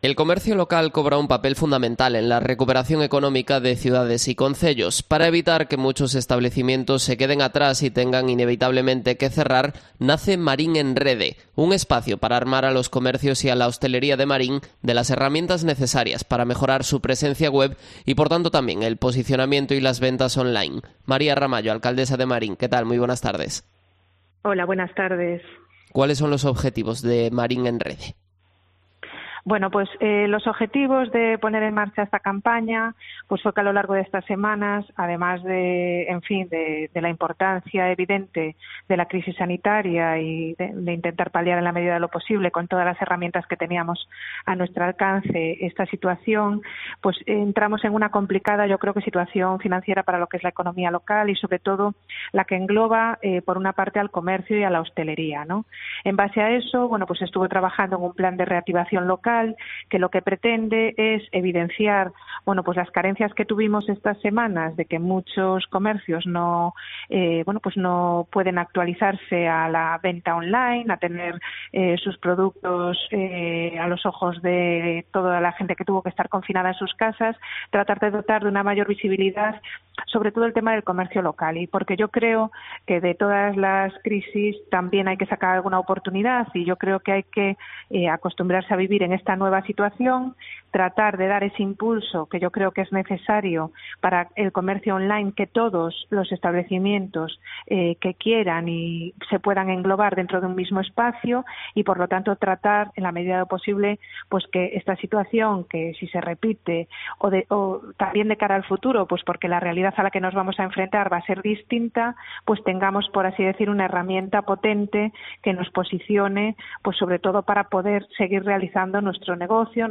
Entrevista a María Ramallo, alcaldesa de Marín